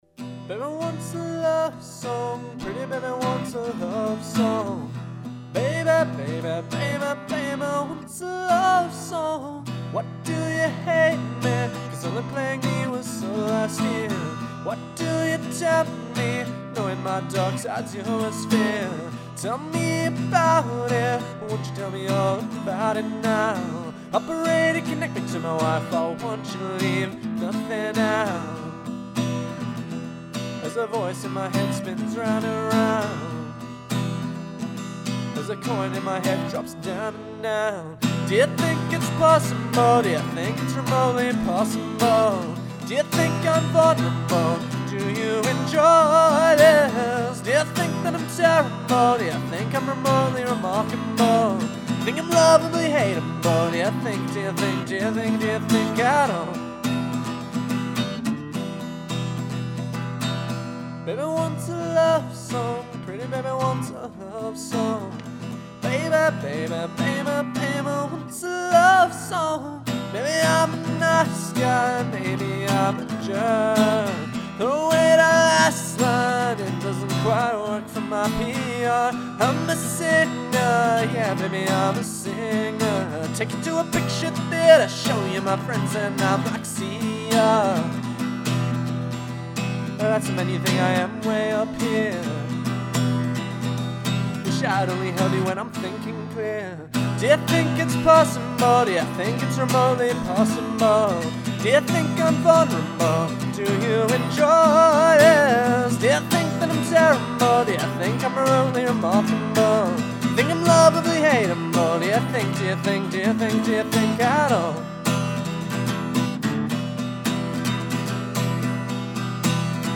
It's a very simple recording using just 2 mics of him singing+ playing the guitar.